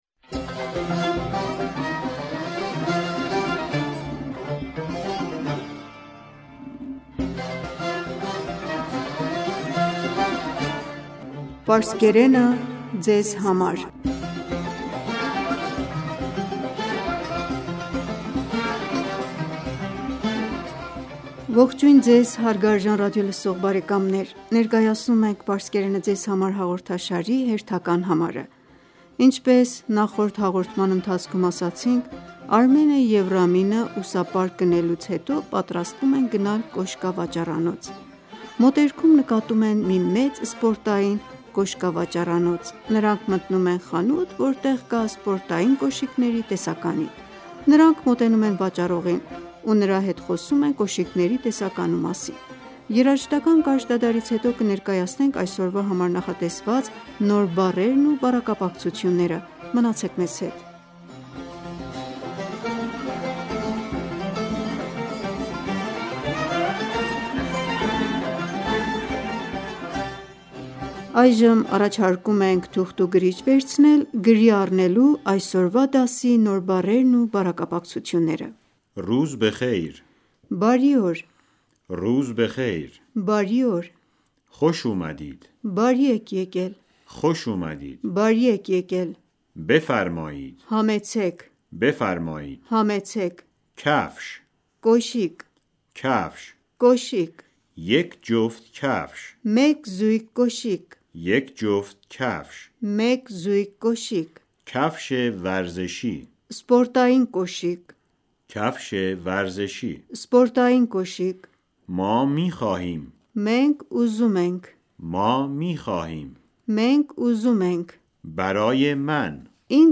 Ողջույն Ձեզ հարգարժան ռադիոլսող բարեկամներ: Ներկայացնում ենք «Պարսկերենը ձեզ համար» հաղորդաշարի հերթական համարը: Ինչպես նախորդ հաղորդման ընթացքում ասացին...
Երաժշտական կարճ դադարից հետո կներկայացնենք այսօրվա համար նախատեսված նոր բառերն ո